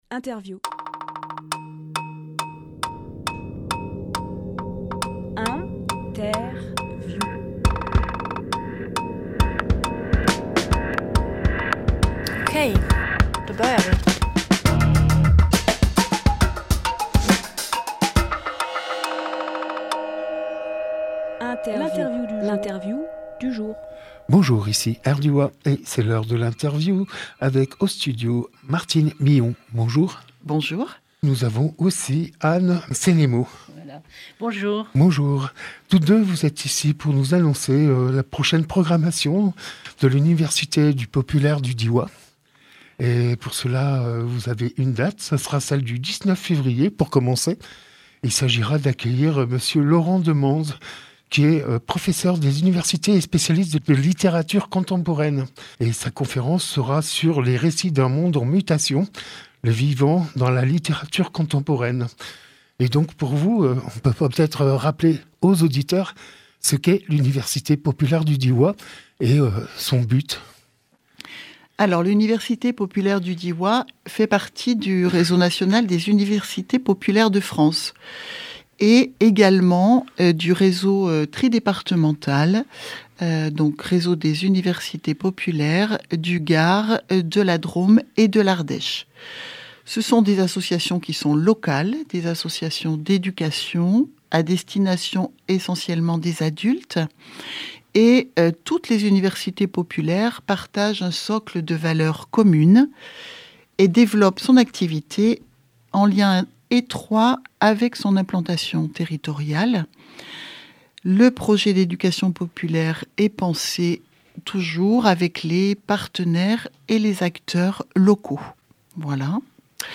Emission - Interview Conférence de l’UPDD
lieu : Studio RDWA